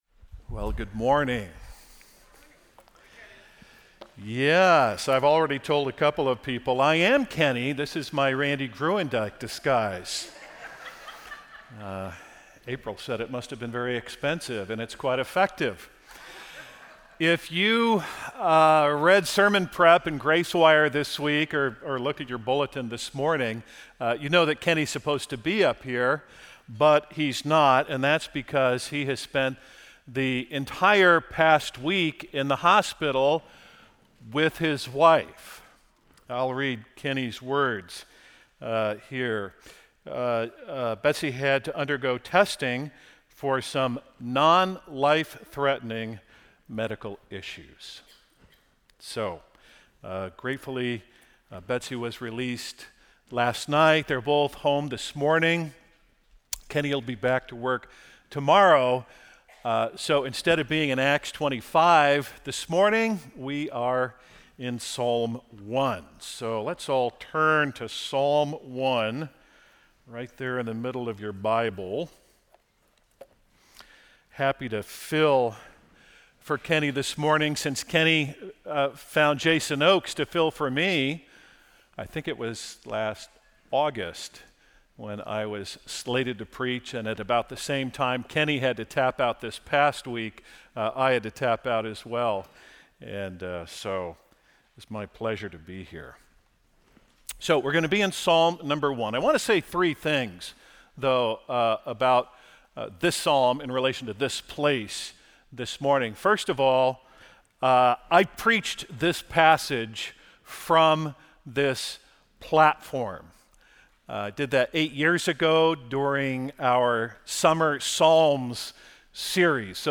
Sermons from Grace EV Free La Mirada